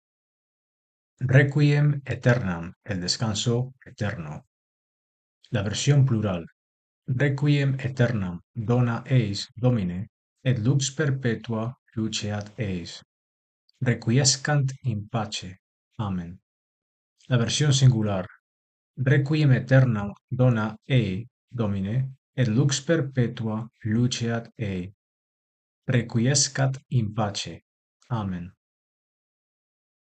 (Descarga el audio de cómo pronunciar el Requiem Aeternam)